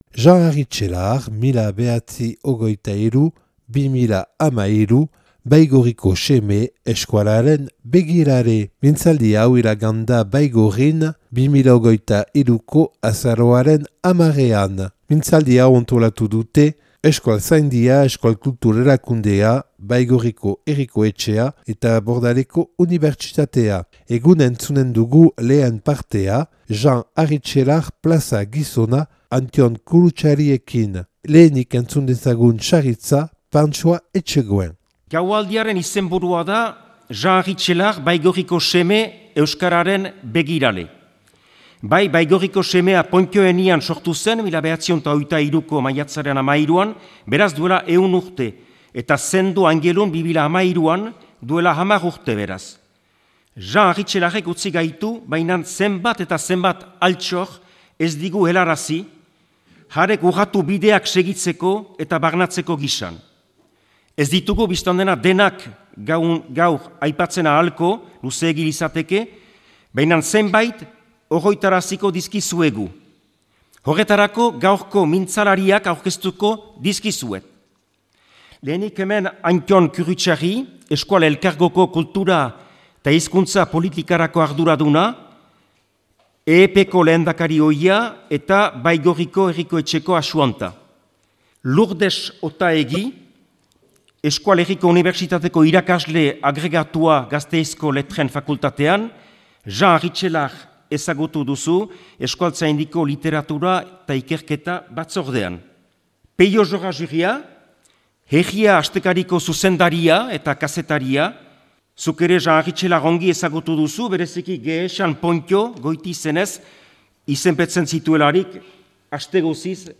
(Baigorrin grabatua 2023. Azaroaren 10an. Antolatzaileak : Euskalzaindia, Euskal Kultur Erakundea, Baigorriko Herriko Etxea eta BordalekoUnibersitateko Haristchelar katedrakoa).